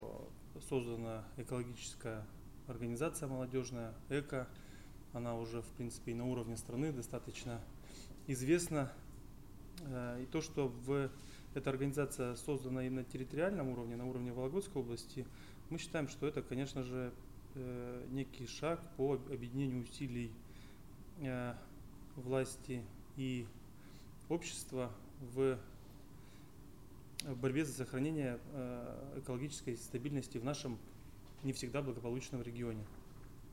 Заместитель губернатора Вологодской области Николай Гуслинский встретился с представителями экологического движения ЭКА в пятницу, 31-го мая.
Николай Гуслинский о деятельности экологов в регионе